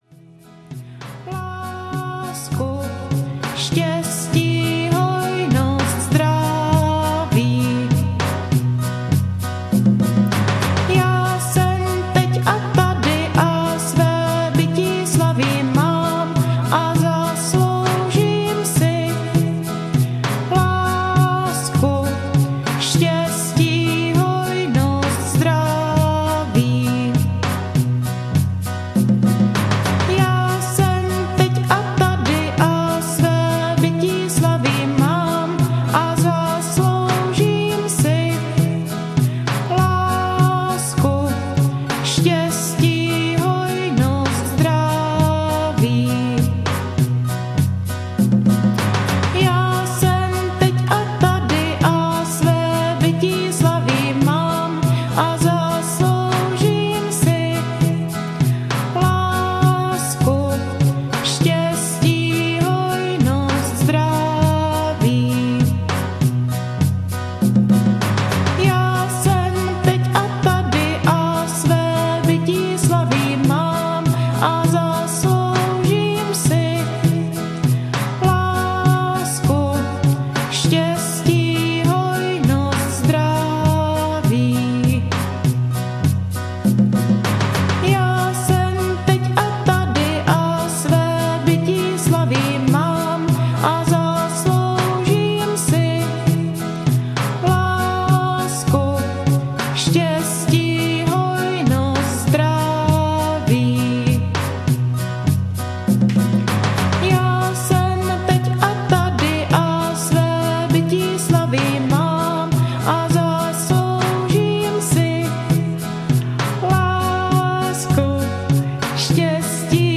Sebevědomí audiokniha